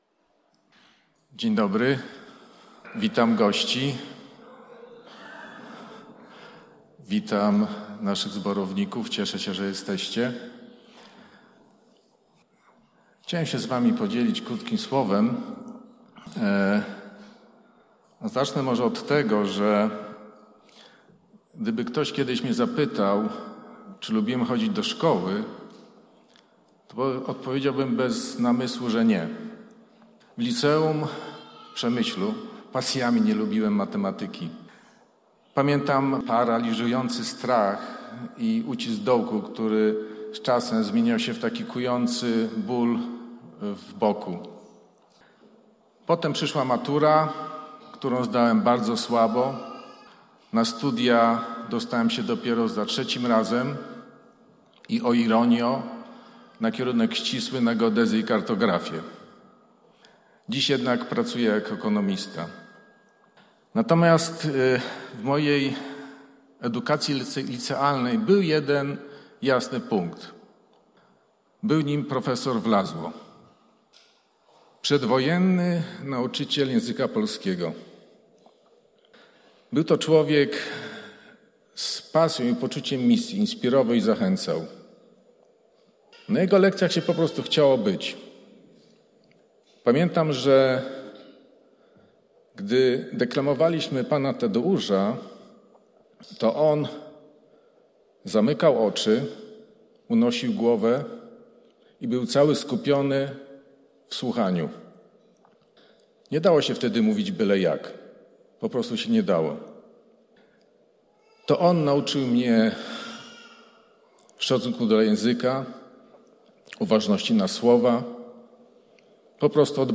Pytanie do przemyślenia po kazaniu: Jaką jedną rzecz zamierzasz zmienić w swoim życiu, aby pójść w drogę za Jezusem jako jego uczeń?